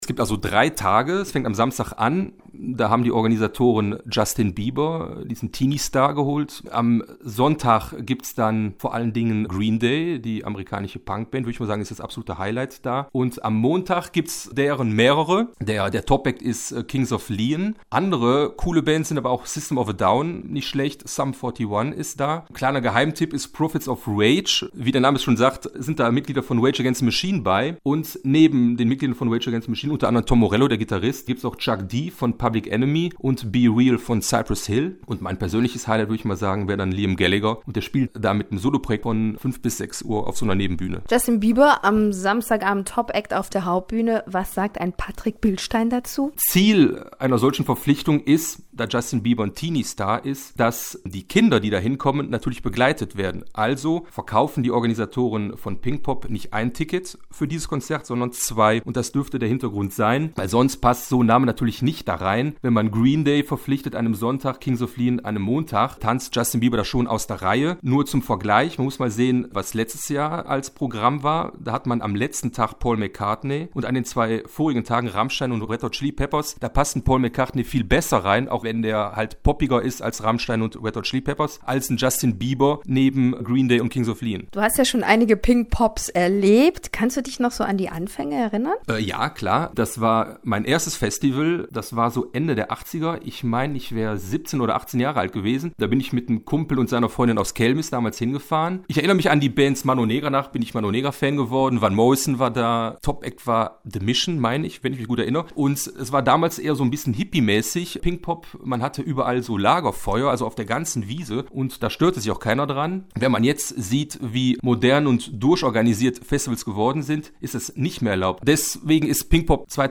Festival-Check